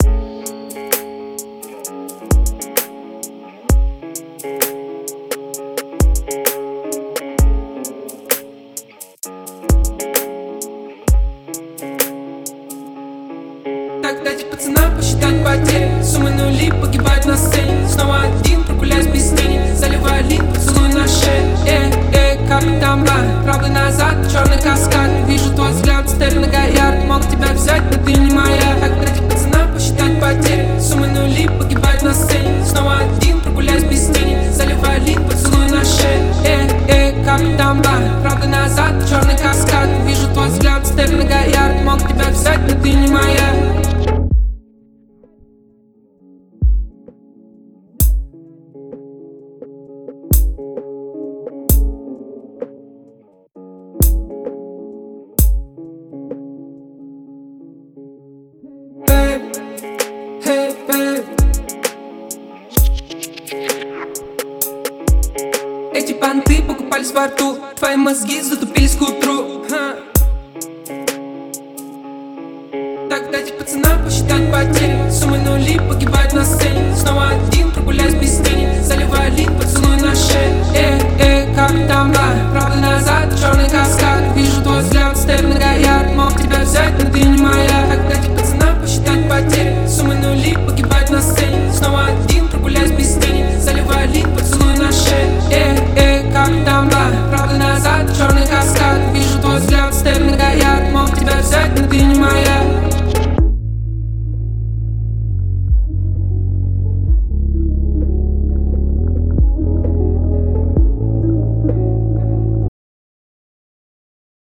это романтическая и мелодичная композиция в жанре поп-рок.